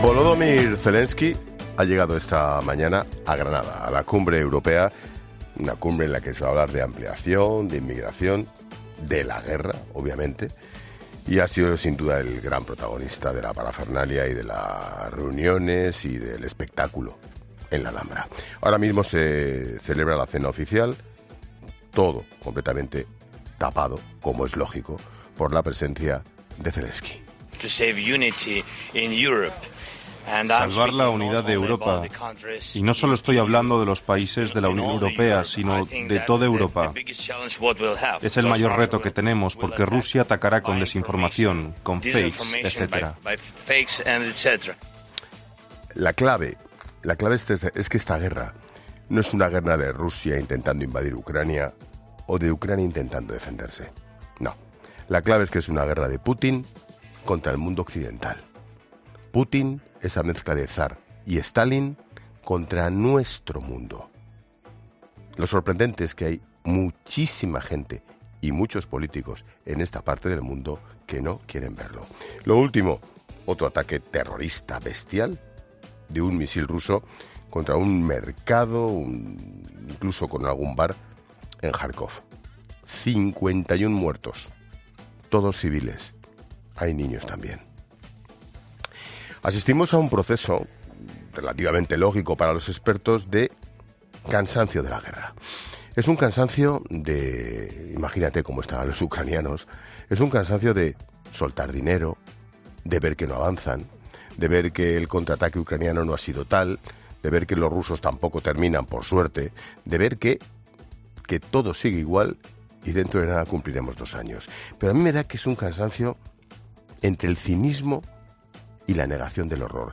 El director de La Linterna analiza la visita del presidente ucraniano a Granada por la celebración de la Cumbre Europea